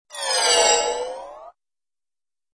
Descarga de Sonidos mp3 Gratis: hechizo 14.